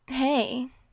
E Girl Hey 2